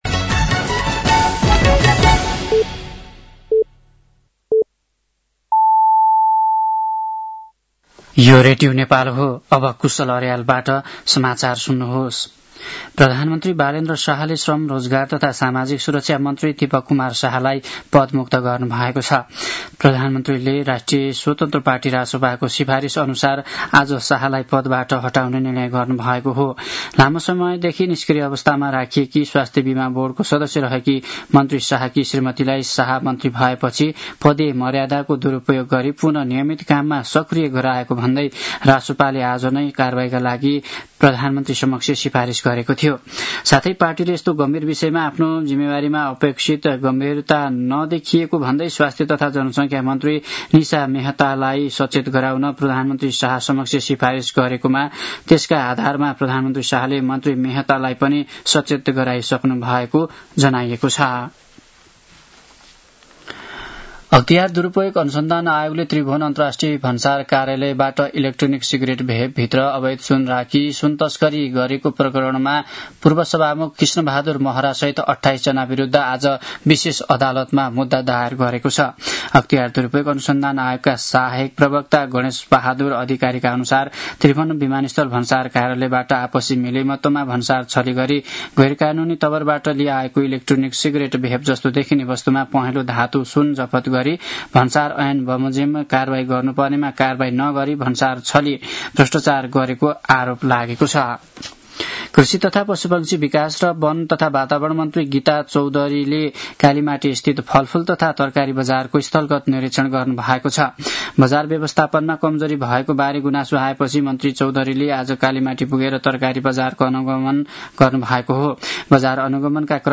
साँझ ५ बजेको नेपाली समाचार : २६ चैत , २०८२